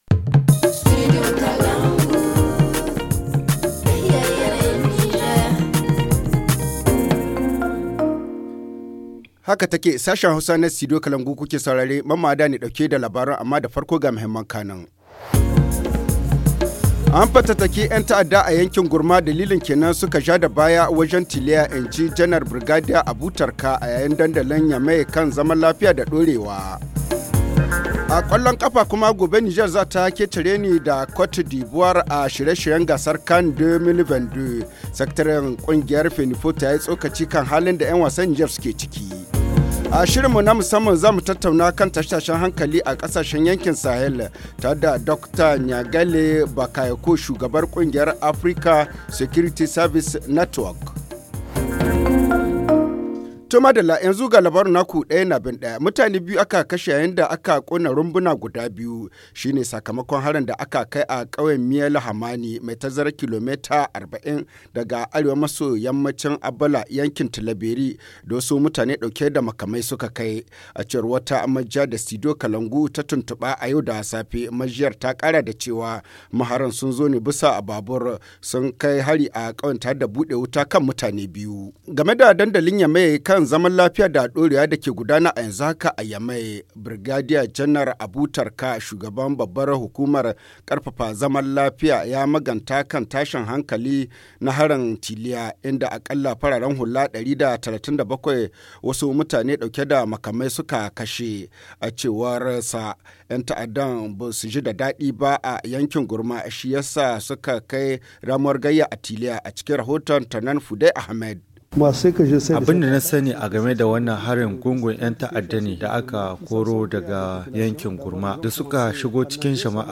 Le journal du 25 mars 2021 - Studio Kalangou - Au rythme du Niger